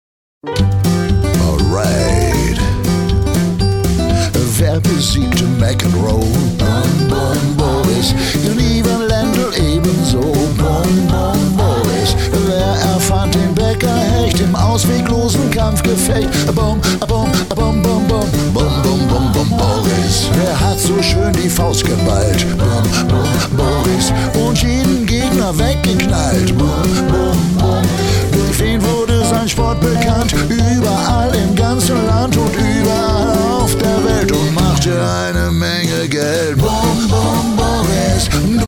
GospelRock